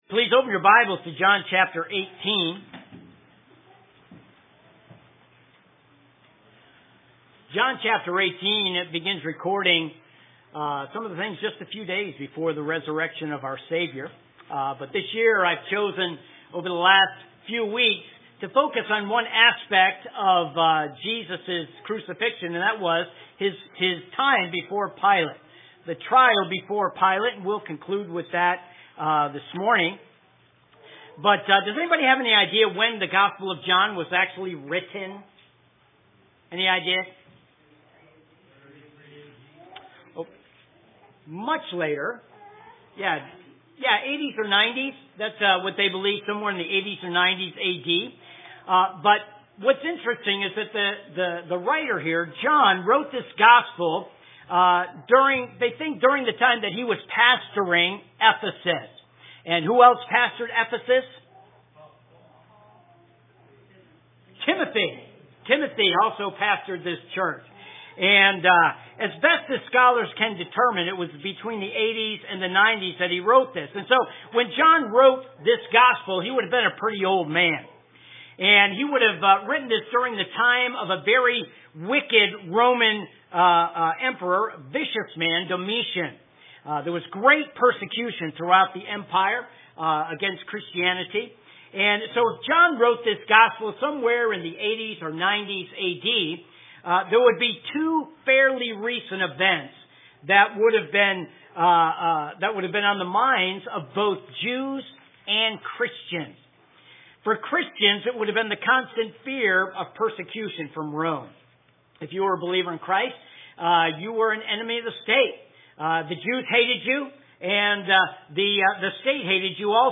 AM Messages